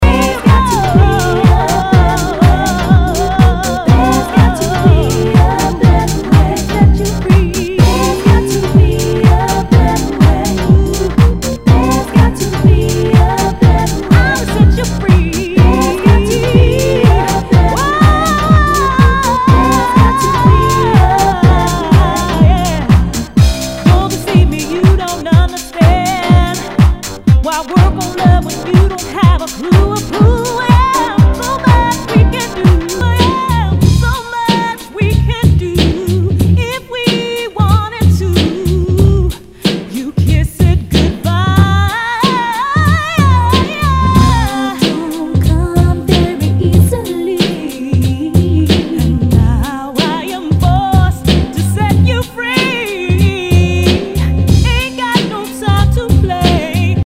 HOUSE/TECHNO/ELECTRO
ナイス！ヴォーカル・ハウス / R&B！